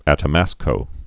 (ătə-măskō)